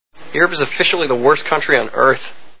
Euro Trip Sound Bites